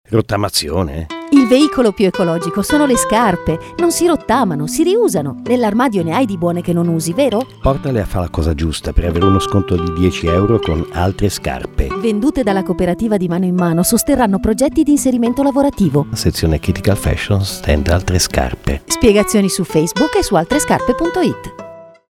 Spot: minimalisti, con poche repliche, su pochissime radio molto specifiche (quelle che la gente sceglie per ascoltare le parole).
Suono low fi: non vale la pena di spendere centinaia di euro per una qualità super hi fi: una onesta qualità digitale è sufficiente, perchè la radio è fruita quasi sempre con una qualità inferiore (una banda passante pessima) e la maggior parte degli ascoltatori ascolta in auto nell’orario dei pendolari, (quindi col rumore del motore acceso);
registrato negli studi di Radio Popolare Milano